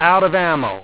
w1_outofammo.wav